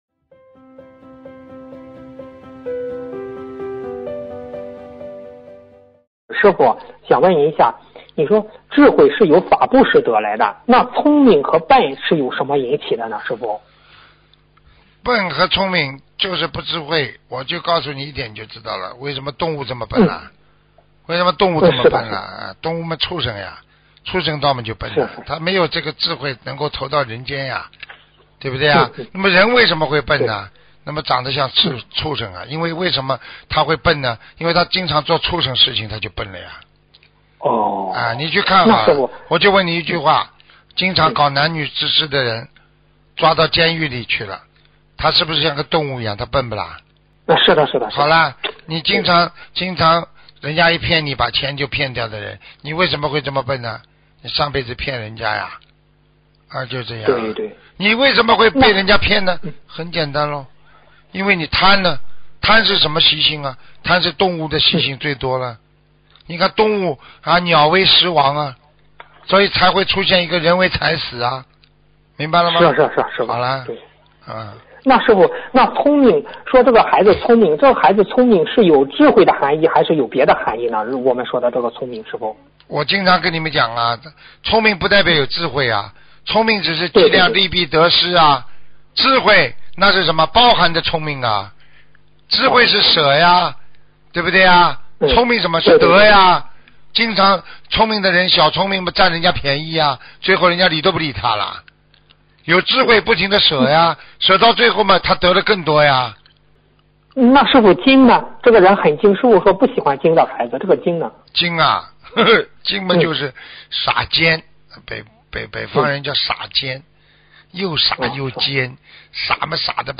音频：聪明不是智慧！问答2017年4月9日！